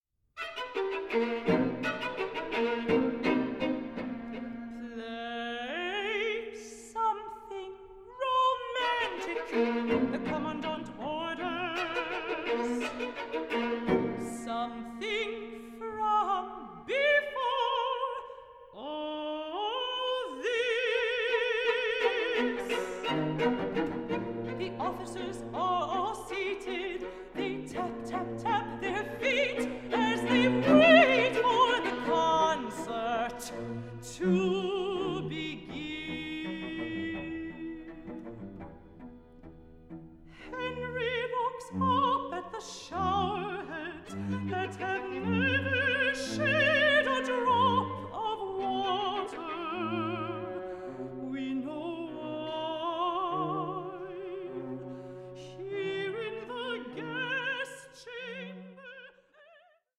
recorded live at Kohl Mansion
mezzo-soprano